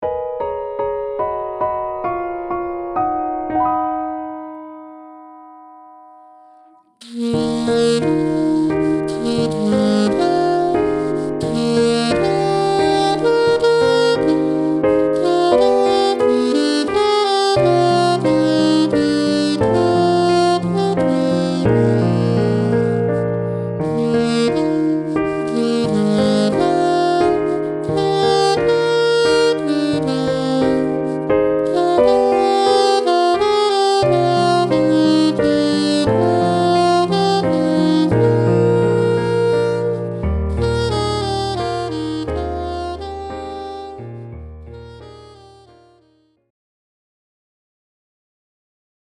F major
Range: low F to C with octave.